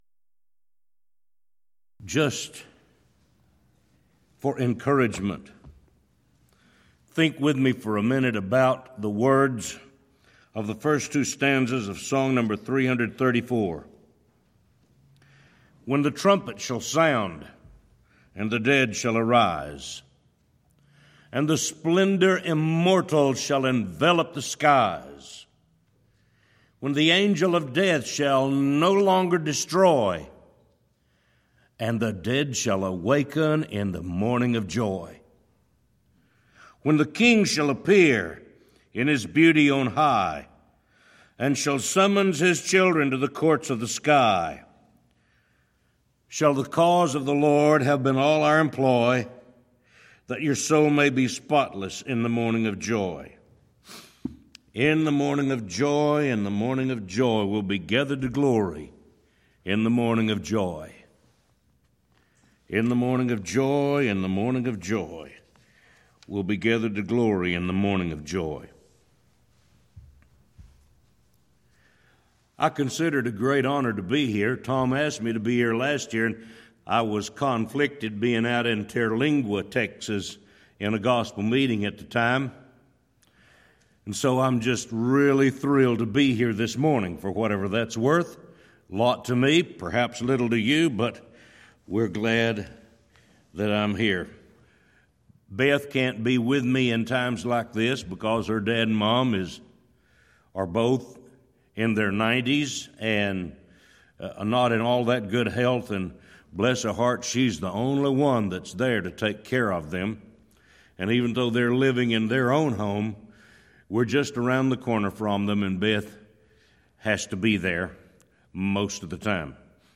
Event: 4th Annual Back to the Bible Lectures Theme/Title: The I Am's of Jesus